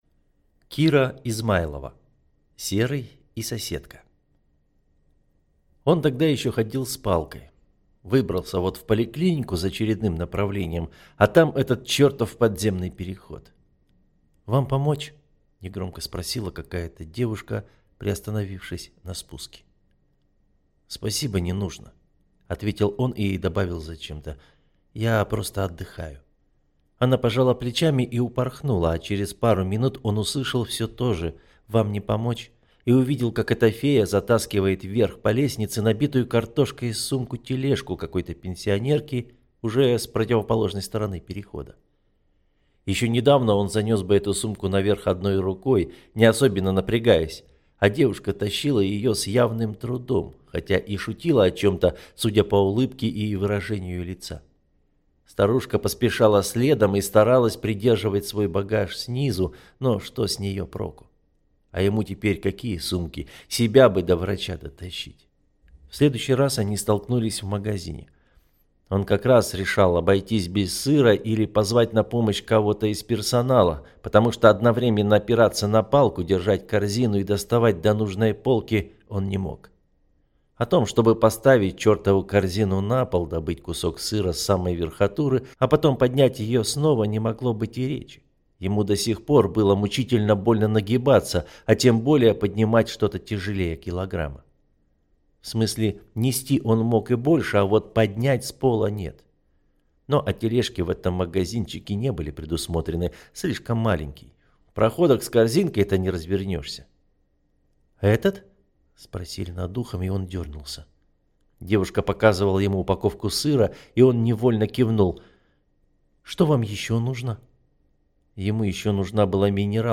Аудиокнига Серый и соседка | Библиотека аудиокниг